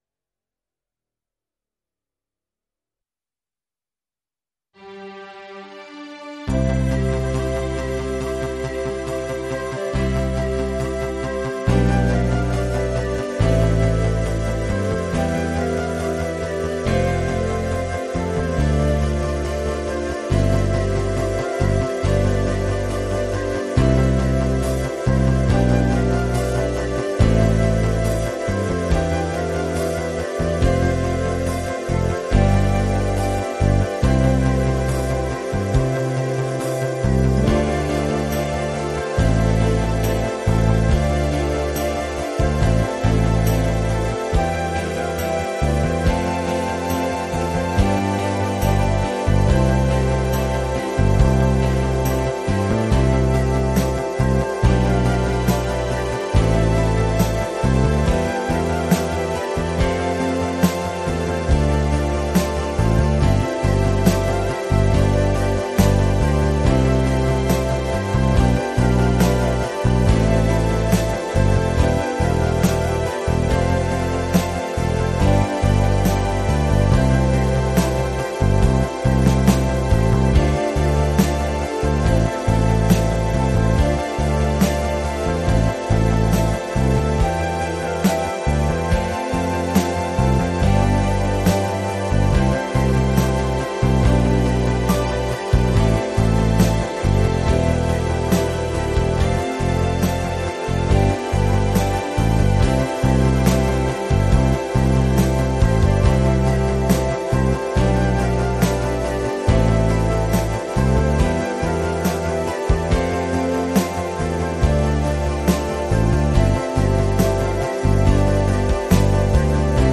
multi-track instrumental version